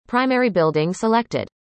All the sounds were generated using text to speech technology.